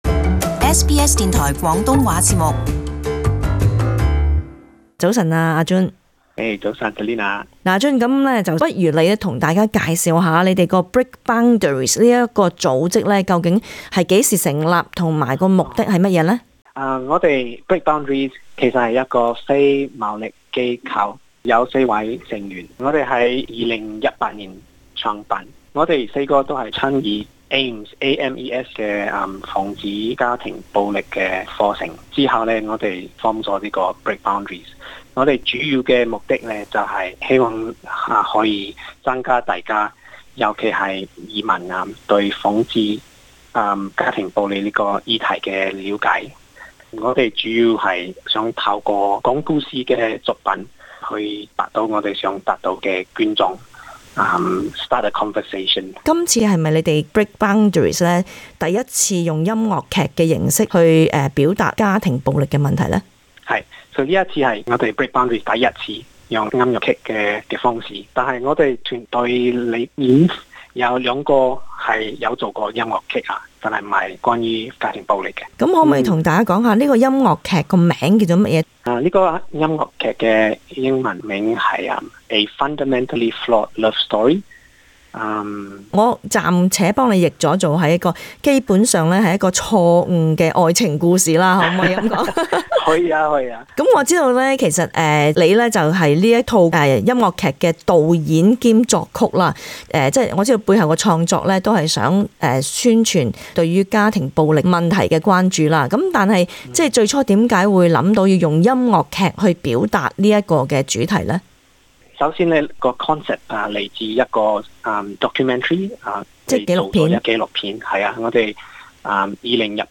【社區專訪】原創音樂劇探討家庭暴力